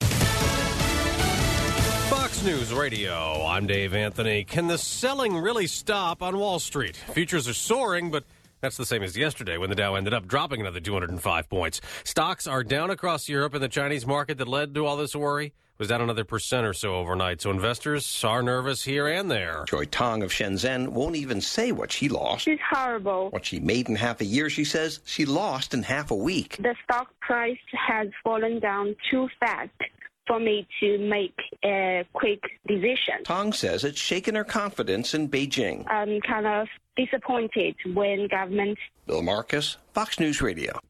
7AM-NEWS-WEDNESDAY-AUG-26.mp3